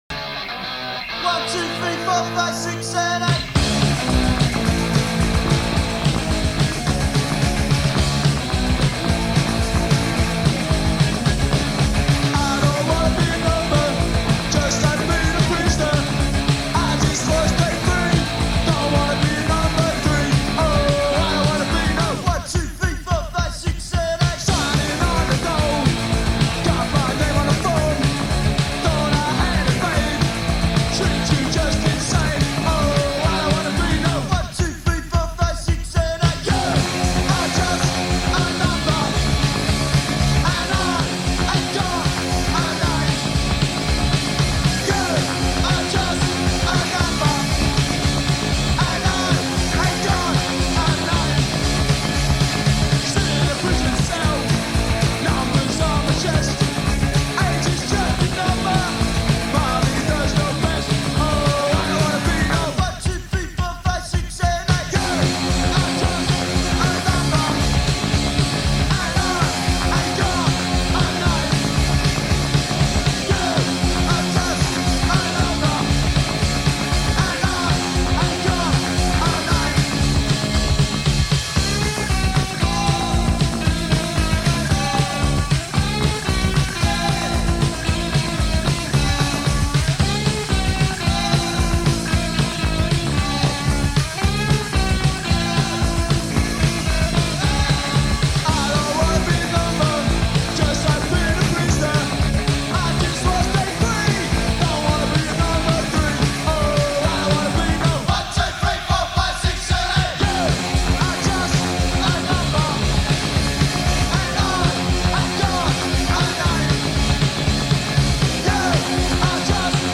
Fully ferocious, even then.
British punk outfit
ferocious yet upbeat sonic attack